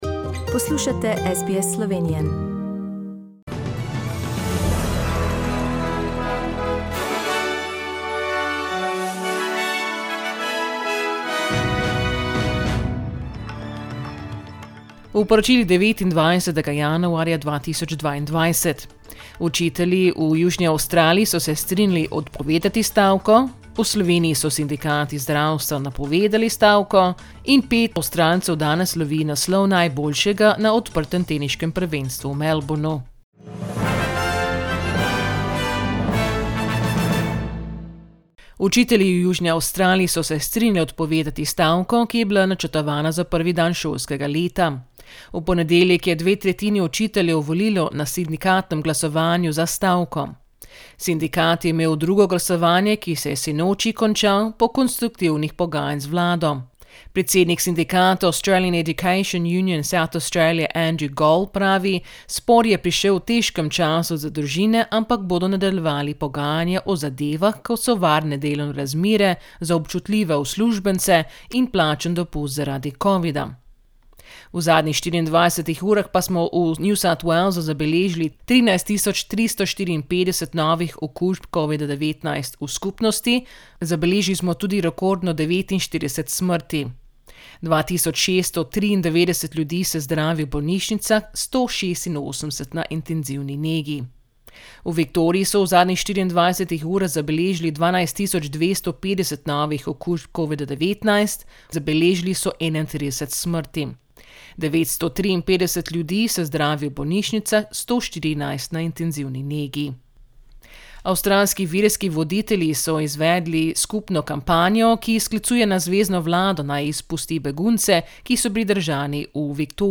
Poročila Radia SBS v slovenščini 29.januarja